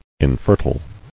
[in·fer·tile]